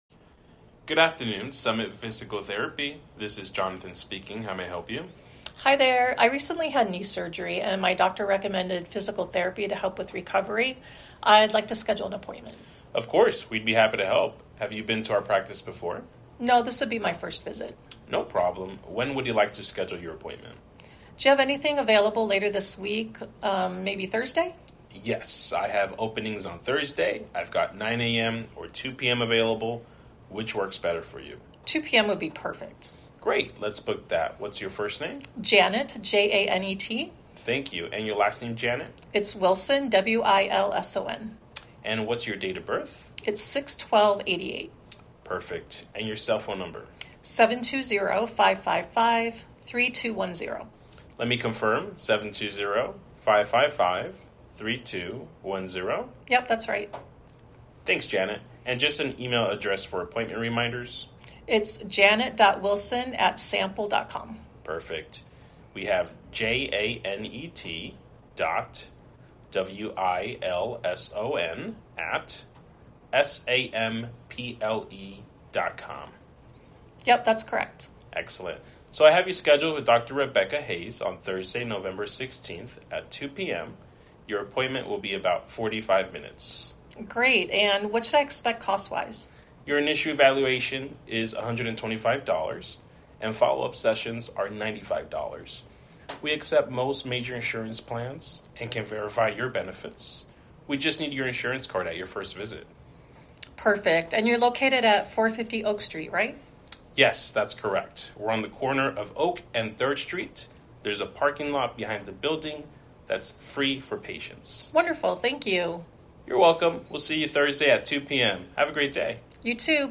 medical-healthcare-virtual-receptionist-sample-call-appointment.mp3